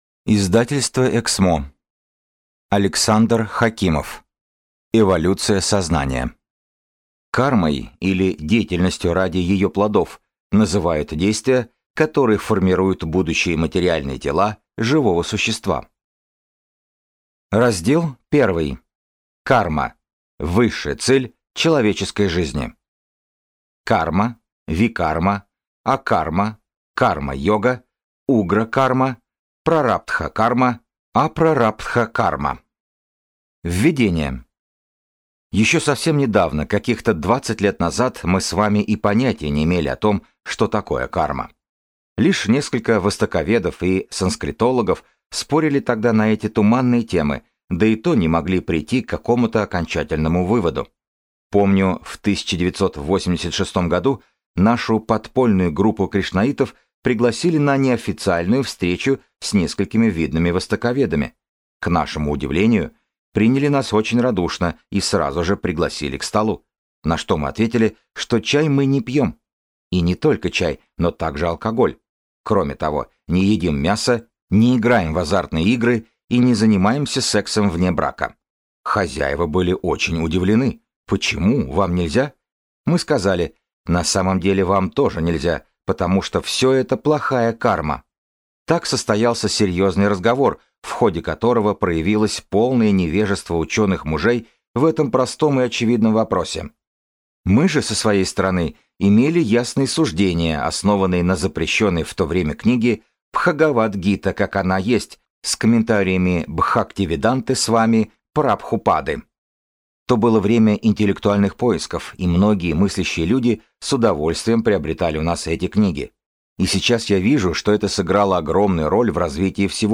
Аудиокнига Эволюция сознания | Библиотека аудиокниг
Прослушать и бесплатно скачать фрагмент аудиокниги